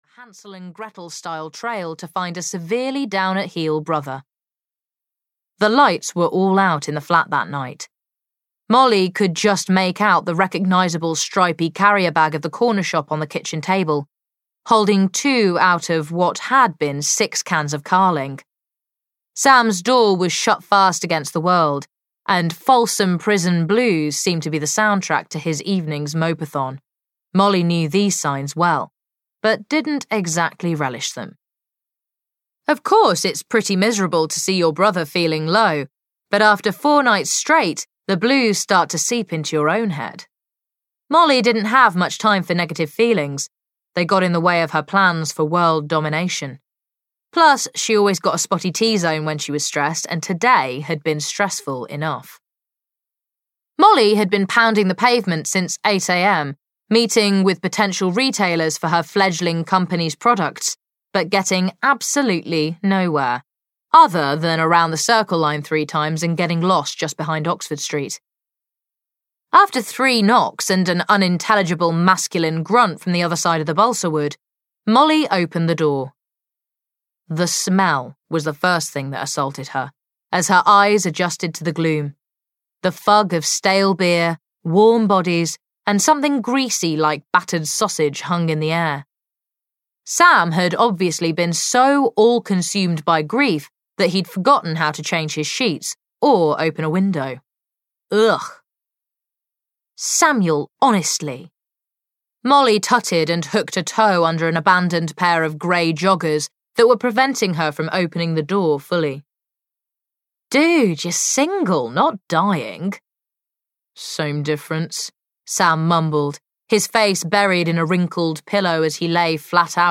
Audio knihaThe Bad Boyfriends Bootcamp (EN)
Ukázka z knihy